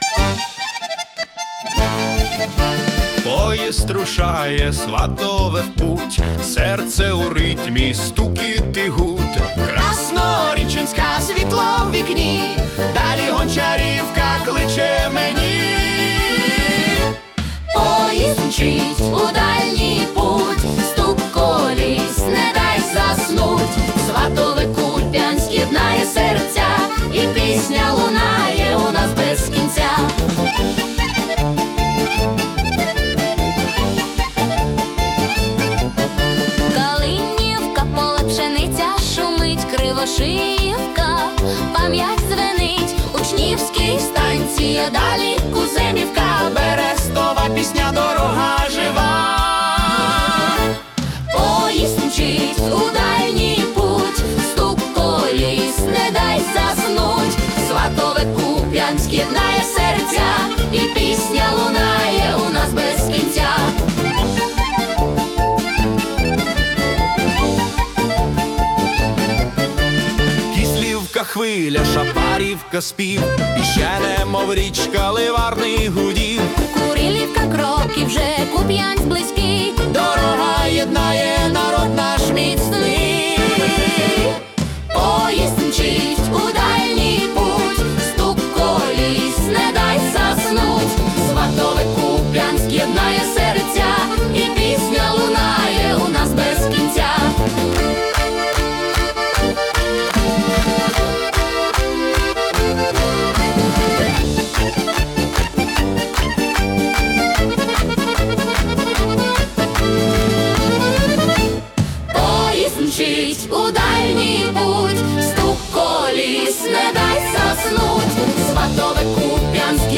кінематографічний фолк-рок (120 BPM)
Фінал композиції звучить переможно і широко.